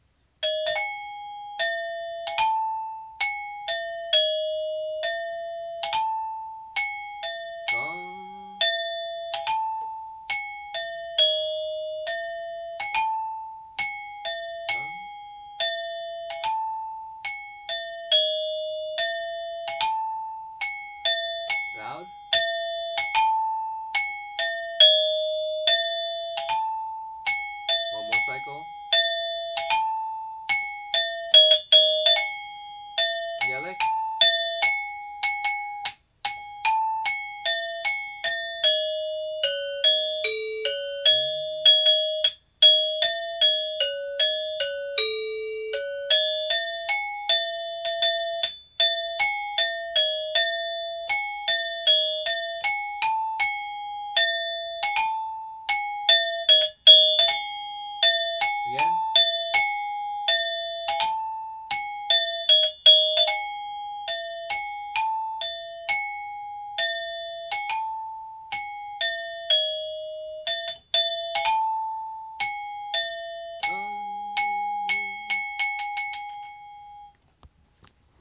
I play Balinese gamelan music in Los Angeles as a member of Sanggar Tujunga.
Rehearsal Recordings